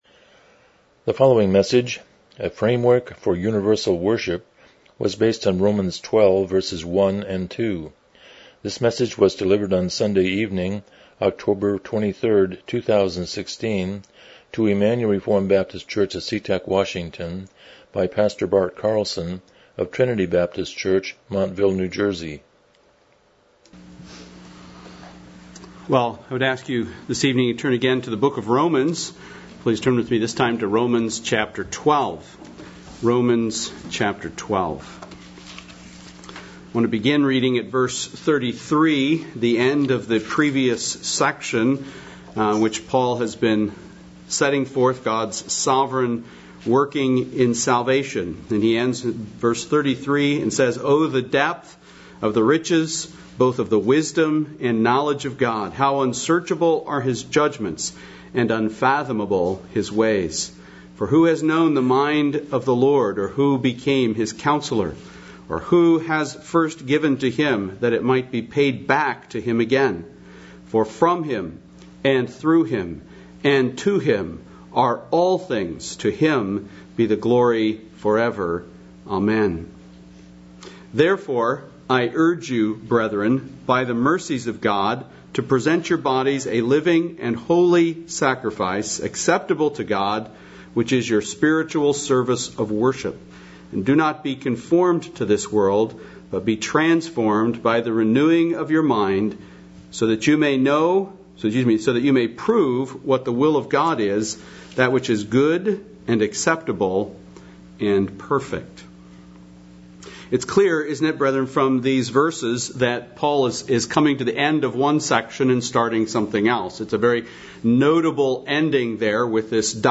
Miscellaneous Passage: Romans 12:1-2 Service Type: Evening Worship « A Fountain of Inexhaustible Hope Worldview